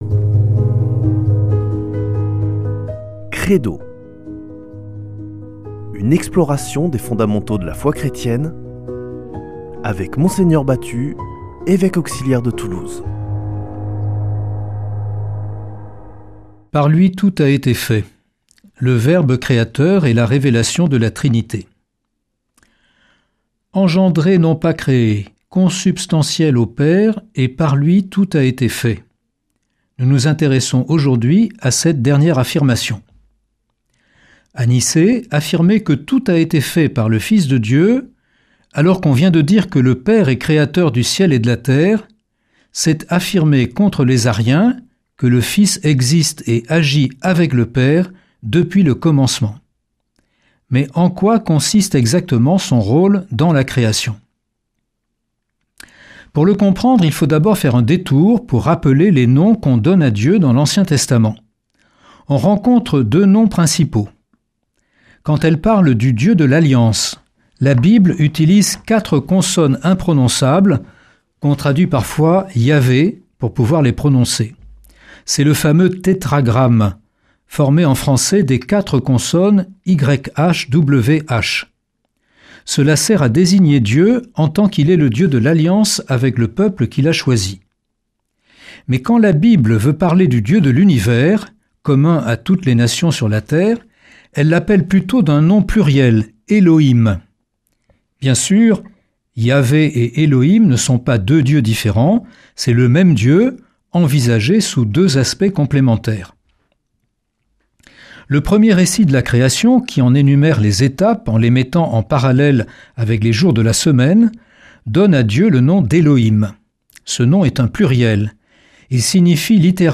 Une émission présentée par Mgr Jean-Pierre Batut Evêque auxiliaire de Toulouse